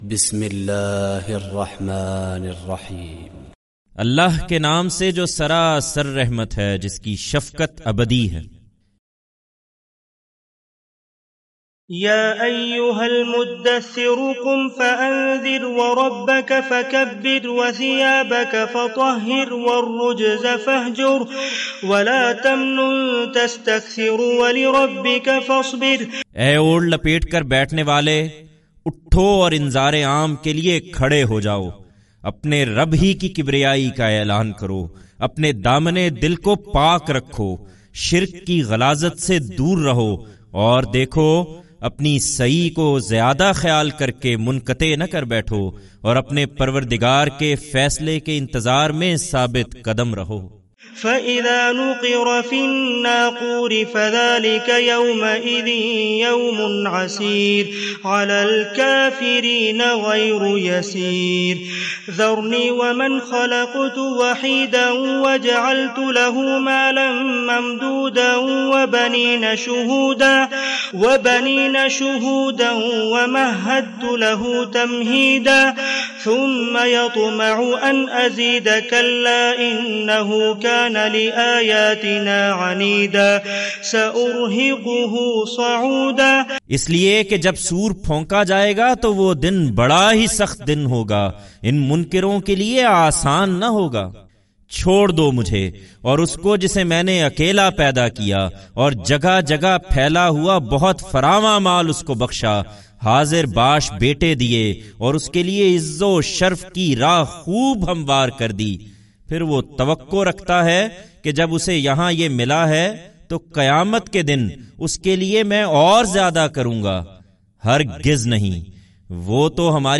The video presents Quranic recitation